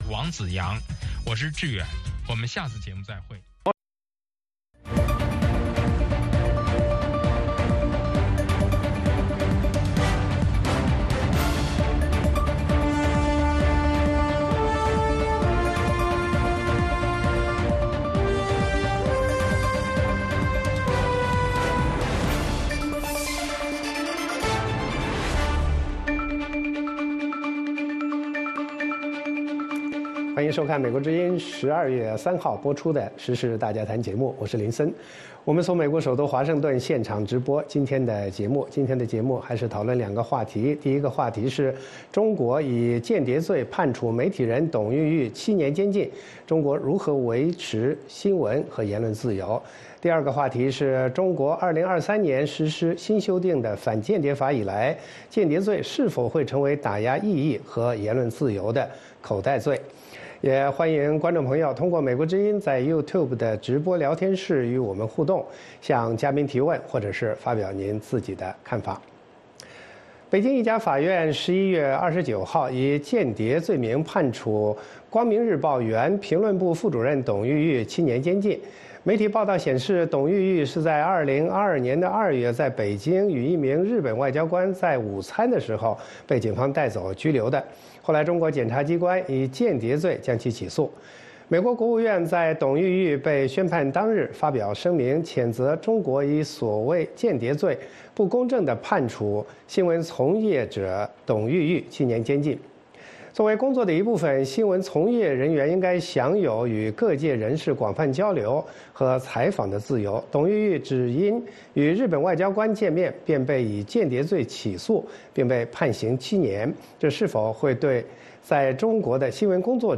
美国之音中文广播于北京时间晚上9点播出《时事大家谈》节目(电视、广播同步播出)。《时事大家谈》围绕重大事件、热点问题、区域冲突以及中国内政外交的重要方面，邀请专家和听众、观众进行现场对话和讨论，利用这个平台自由交换看法，探索事实。